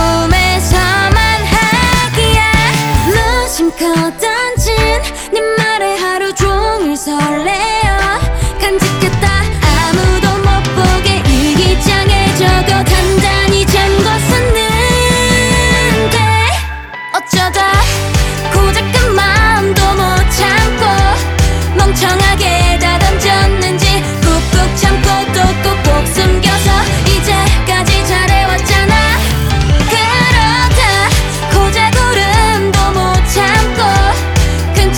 Жанр: Поп / Рок / K-pop